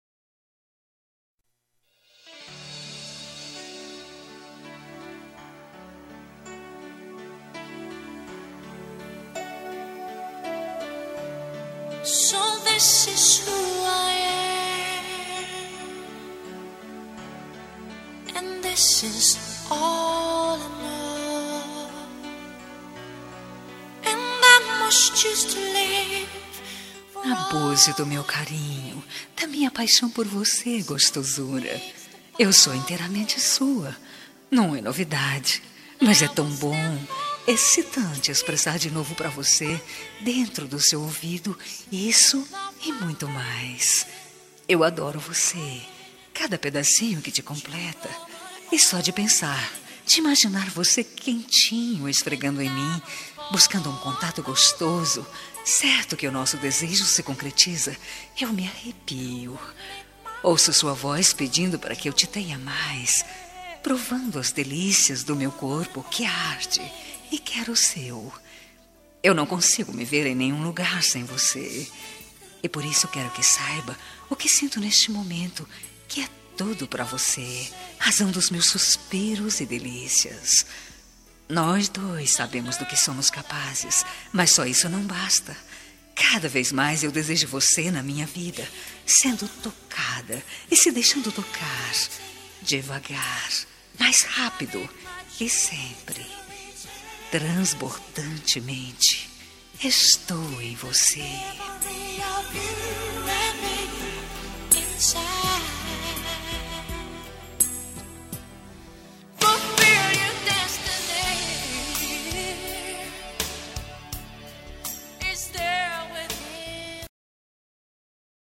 Telemensagem Picante – Voz Masculina – Cód: 79218